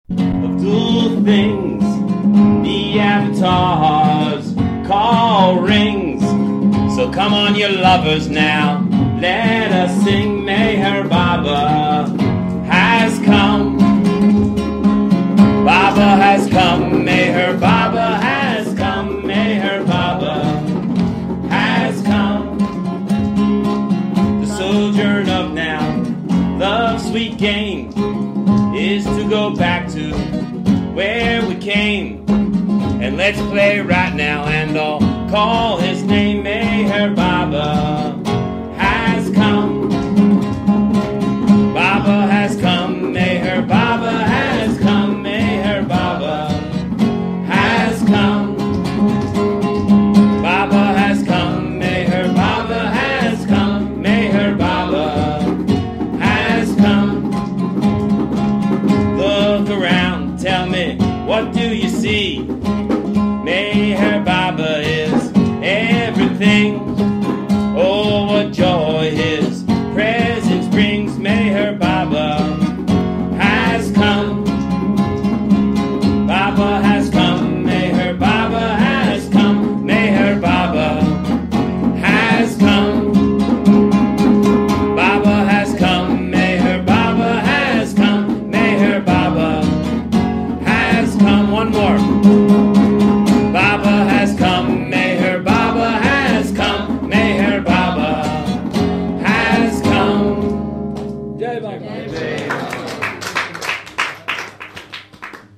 A collection of talks, stories, discussions and musical performances by Avatar Meher Baba's mandali and lovers, recorded primarily in Mandali Hall, Meherazad, India.